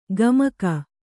♪ gamaka